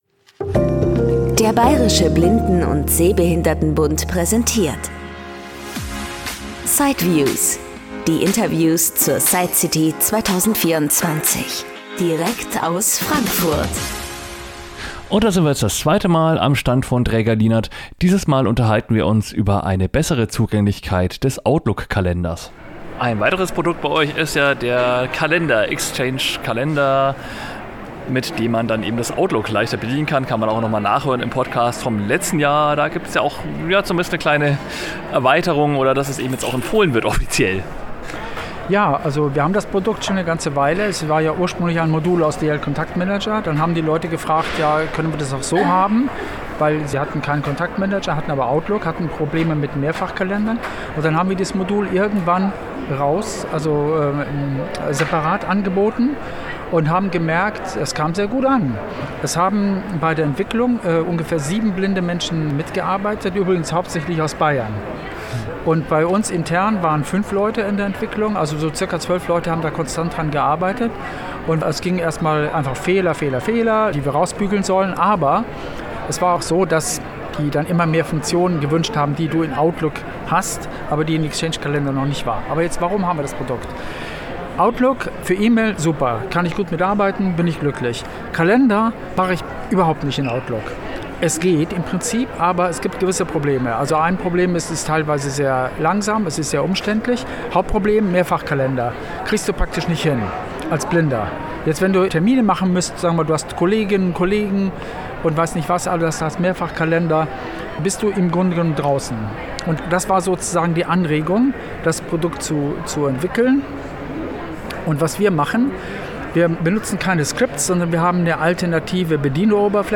In Teil 2 des Interviews mit Draeger Lienert geht es um den DL